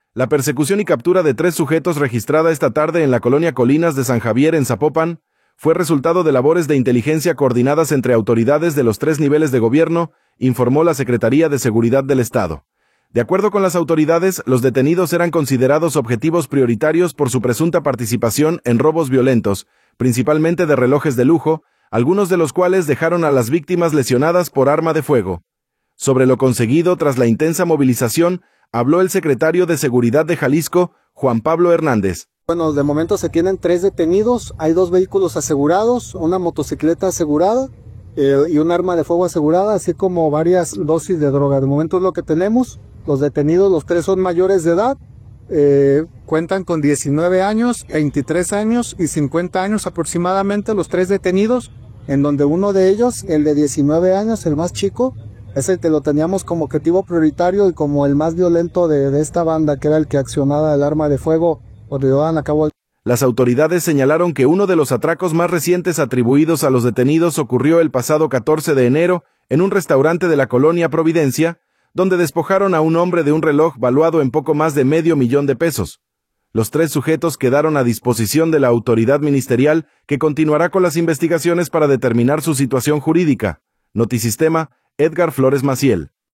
Sobre lo conseguido tras la intensa movilización habló el secretario de Seguridad de Jalisco, Juan Pablo Hernández.